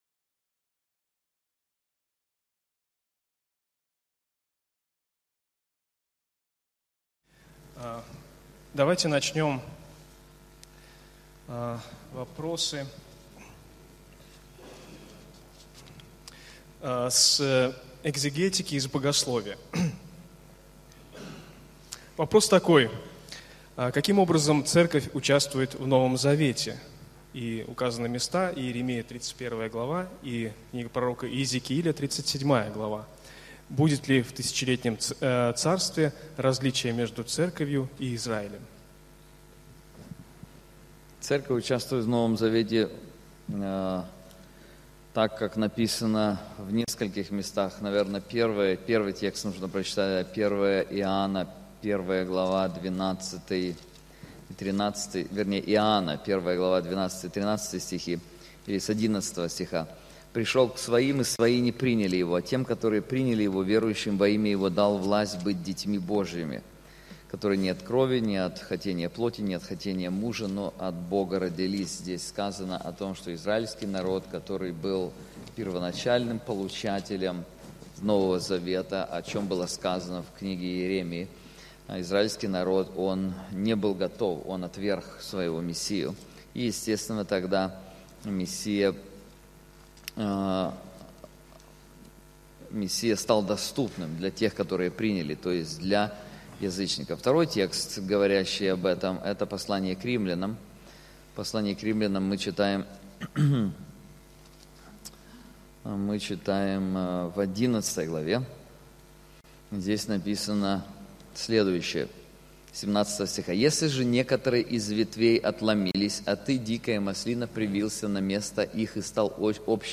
Вопросы и ответы (часть 2)